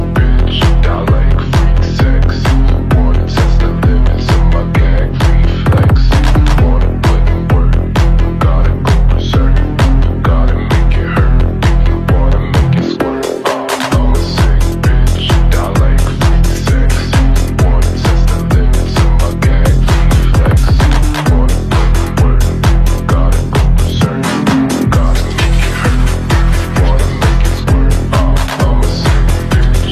Жанр: Хаус